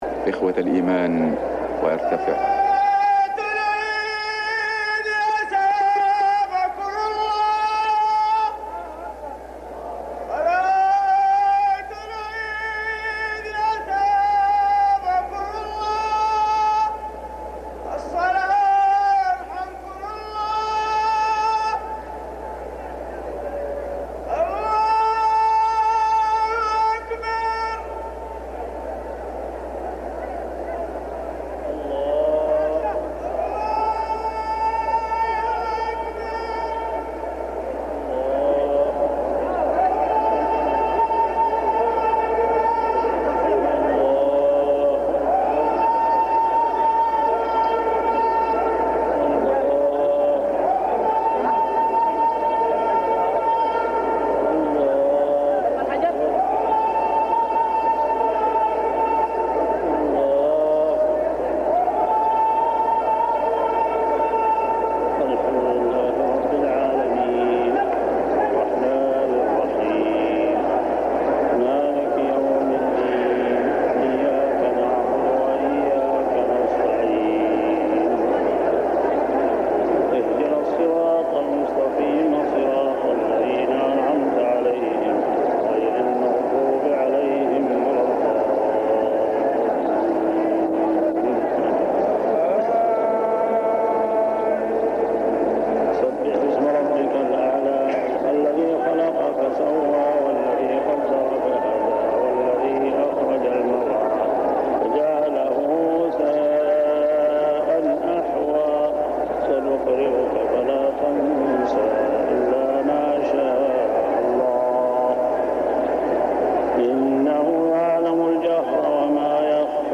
صلاة عيد الأضحى 1399هـ سورتي الأعلى و الغاشية كاملة | Eid prayer Surah Al-a’ala and Al-gashiya > 1399 🕋 > الفروض - تلاوات الحرمين